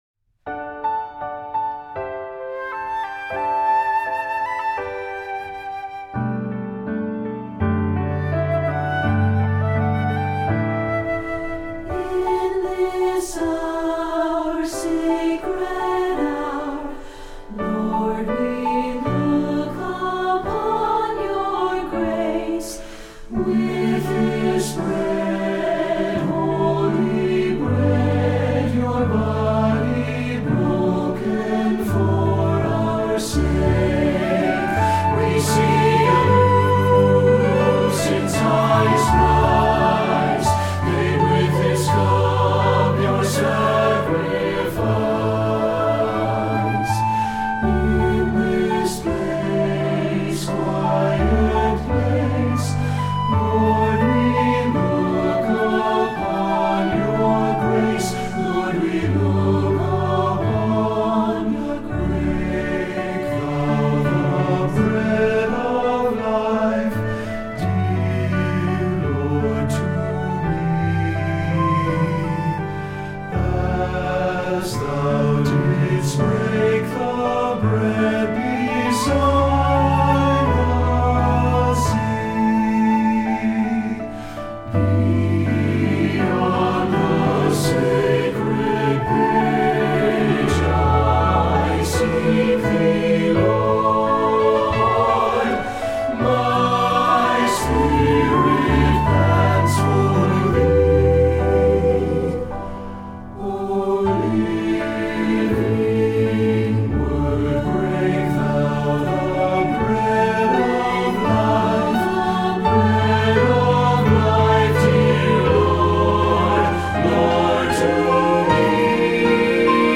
Voicing: SATB and Flute